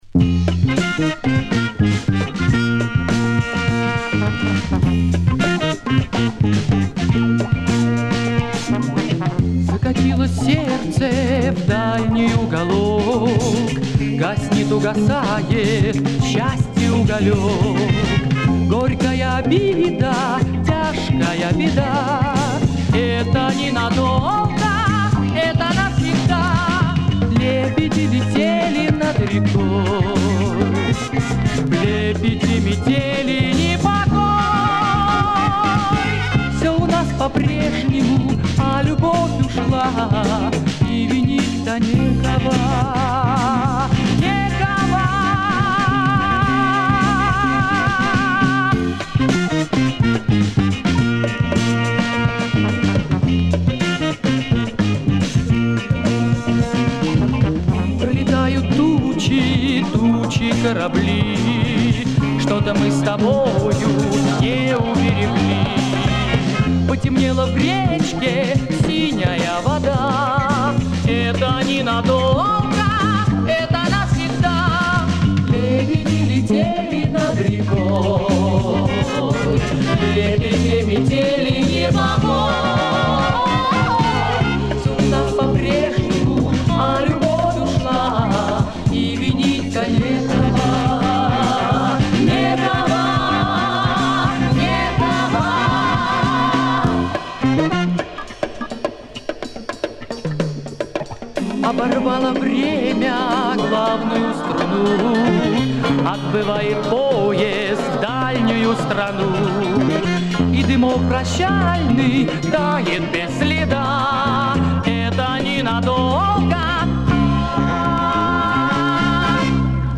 Жанр: Советская эстрада
Хруст иголки порадовал
Специально оставил похрустывание,ностальгия...